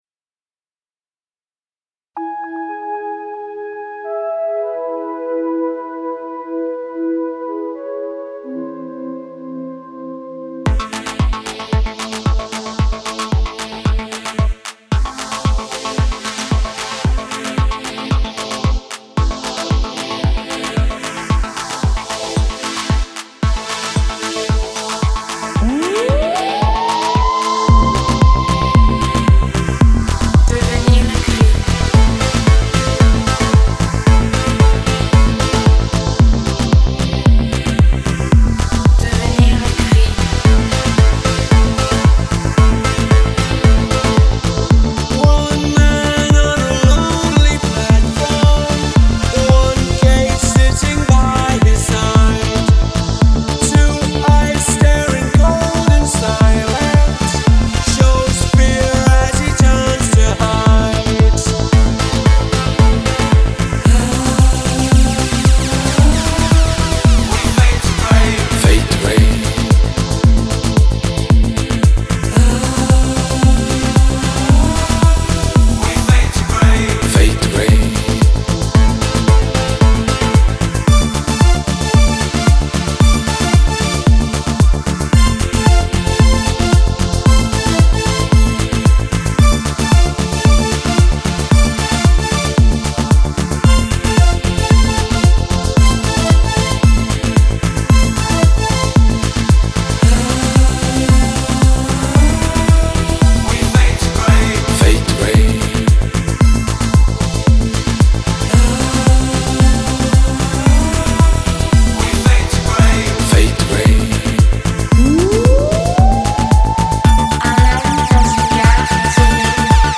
Прошу оценить сведение, укажите ошибки недочеты